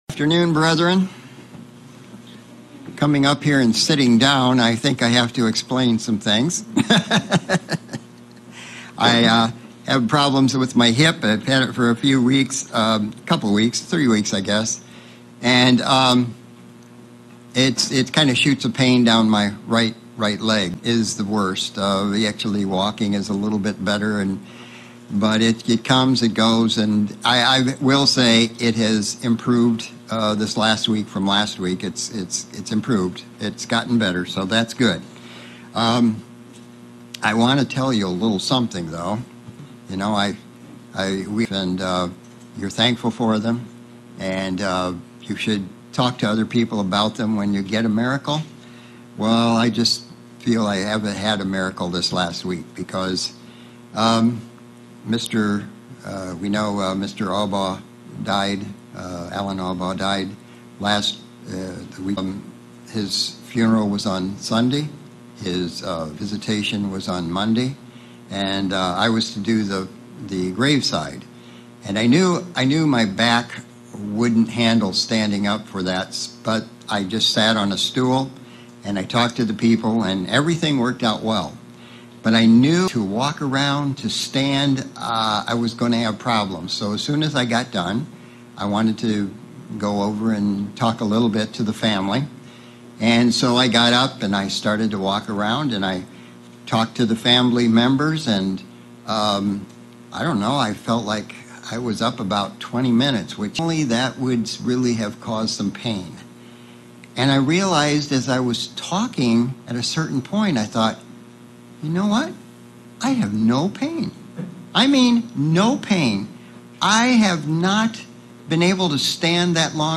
Sermon looking at the what God is doing with the day of Pentecost and how they are used to fulfill his plan. 1. The Change of Priesthood 2. The High Priest 3. The Sacrifices.